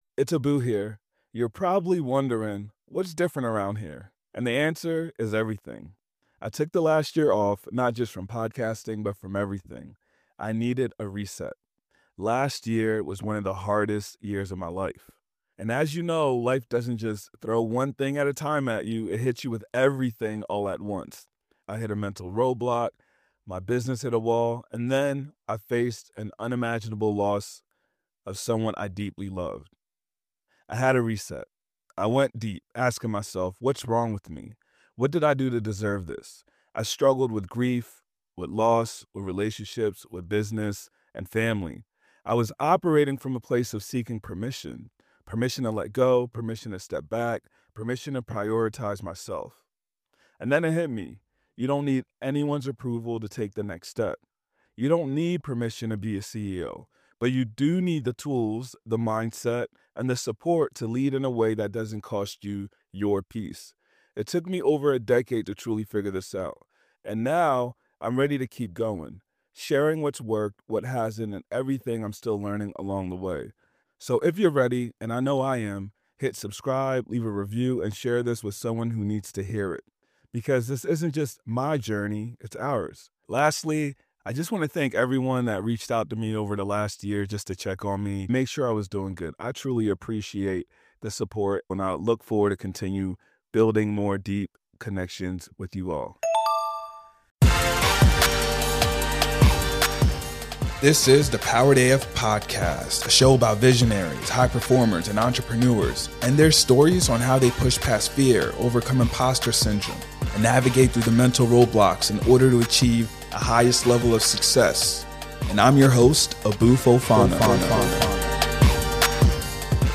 Our last episode with Charm City Buyers was so good that I decided to bring them back for a LIVE Q&A session on this week's Powered AF Powered Chat. Tune in as we listen to the audience and answer all of the questions many people may have towards real estate, entrepreneurship and more.